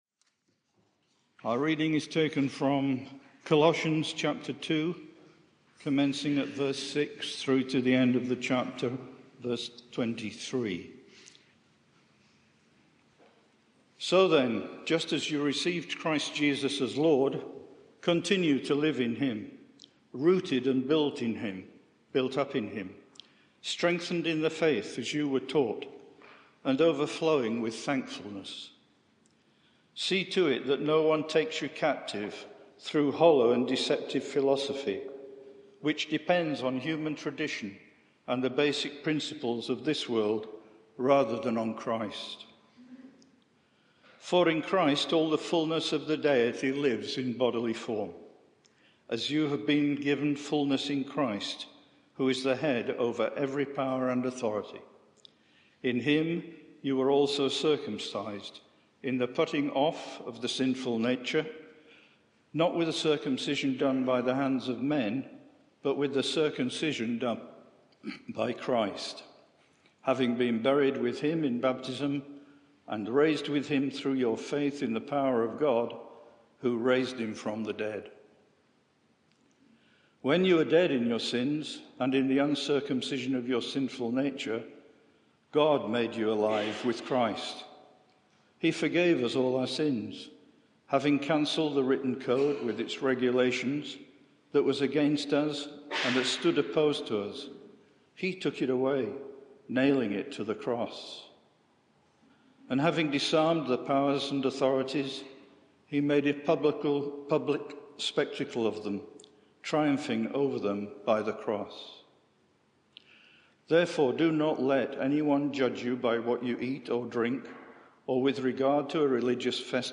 Media for 11am Service on Sun 26th Jun 2022 11:00 Speaker
Sermon